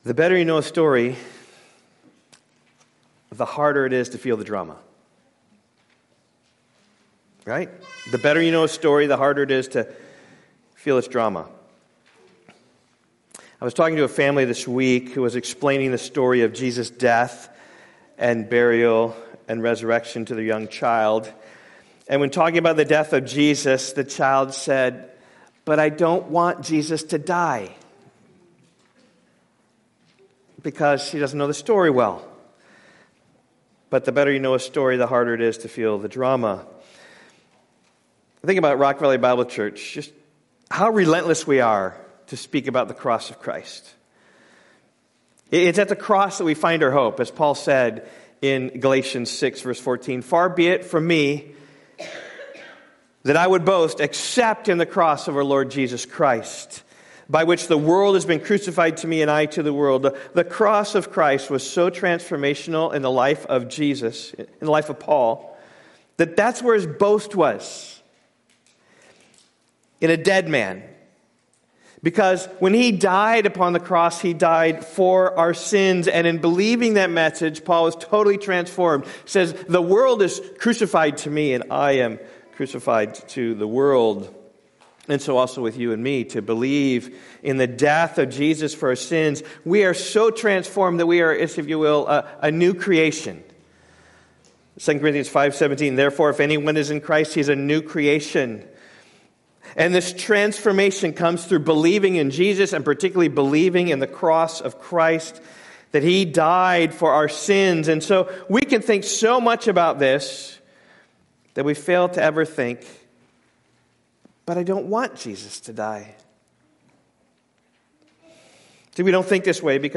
Sermon audio from Rock Valley Bible Church.